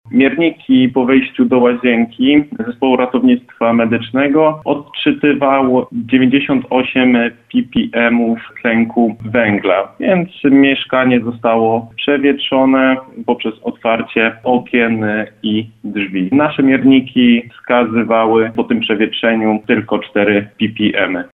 19strazak.mp3